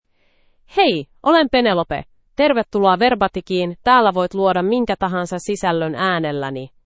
Penelope — Female Finnish AI voice
Penelope is a female AI voice for Finnish (Finland).
Voice sample
Listen to Penelope's female Finnish voice.
Penelope delivers clear pronunciation with authentic Finland Finnish intonation, making your content sound professionally produced.